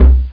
1 channel
DRUM8.mp3